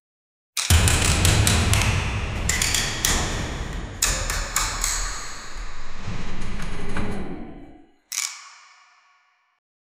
a-futuristic-door-opens-kay5l6gn.wav